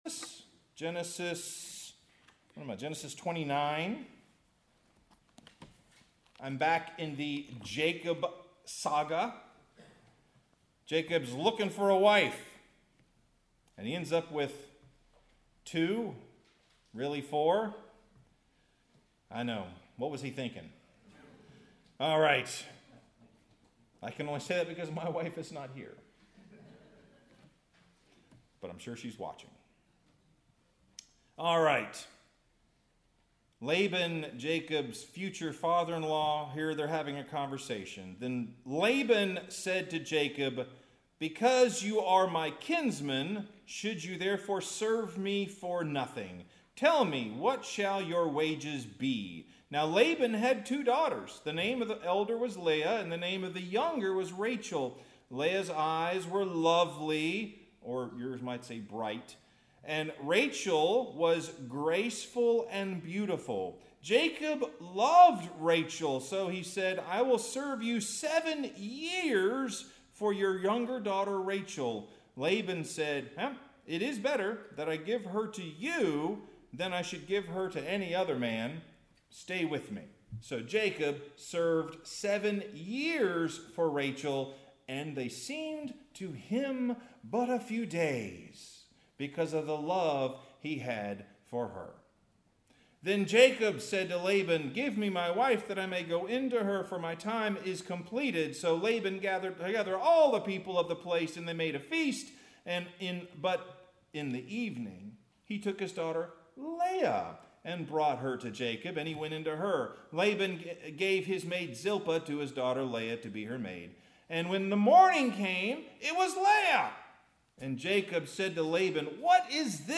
Sermon – The Daily Grind Genesis 29:15-30; 2 Corinthians 12:5b-10 Farmville Presbyterian Church 11/09/25 I don’t know about you, but last week, we had a pretty big time here in worship.